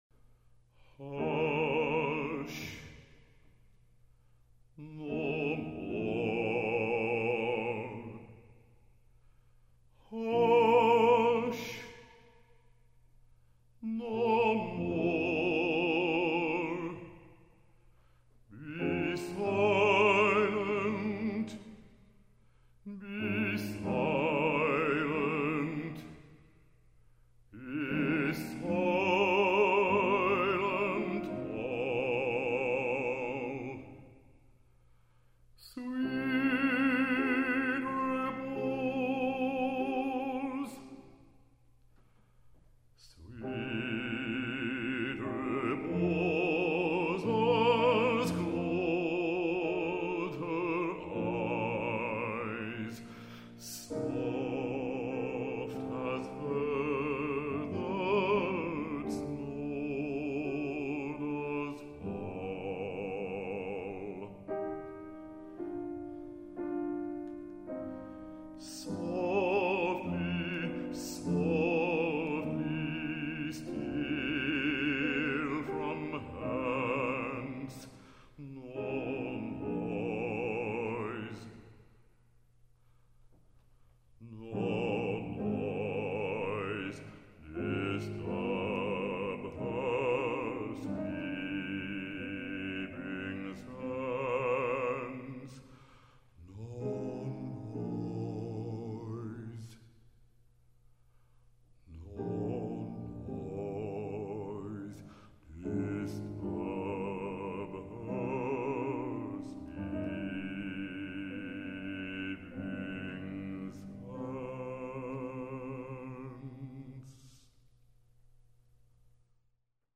Opera Demos